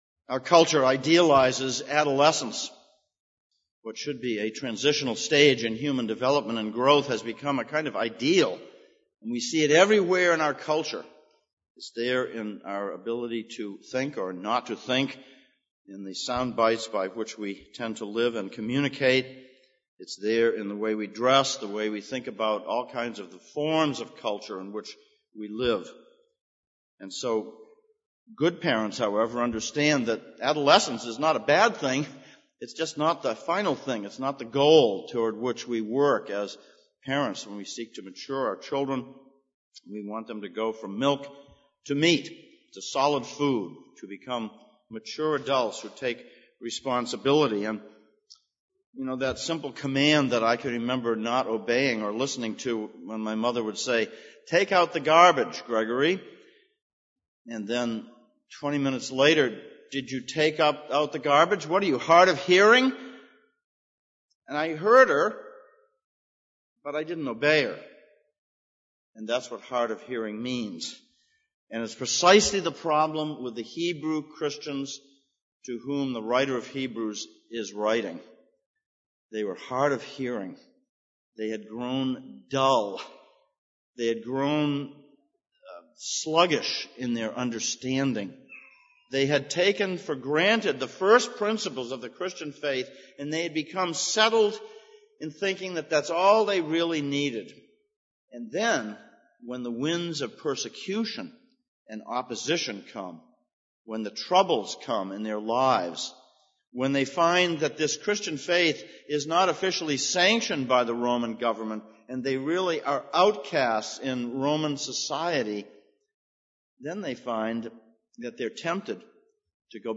Passage: Hebrews 5:11-6:12, Isaiah 5:1-7 Service Type: Sunday Morning